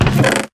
chestopen.ogg